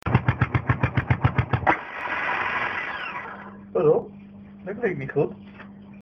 Geluid Savage  =====>